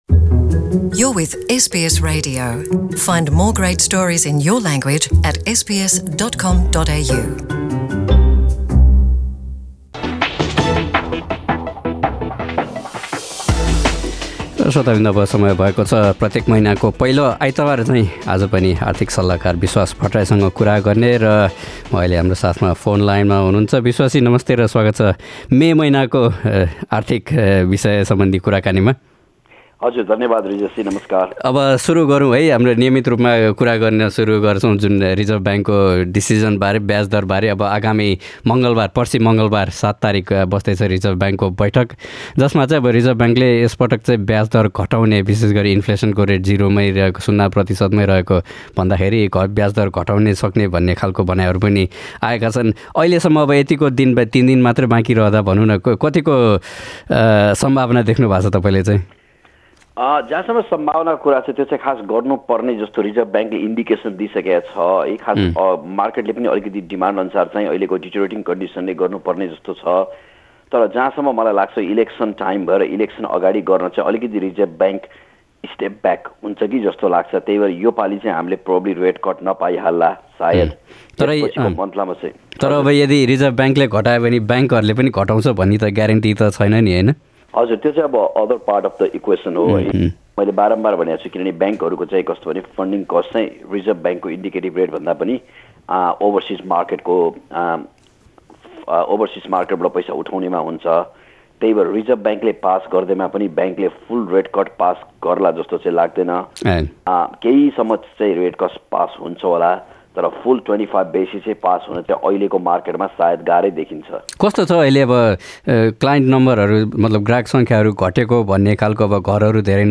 हाम्रो मासिक वित्तीय कुराकानीको यो मे महिनाको संस्करणमा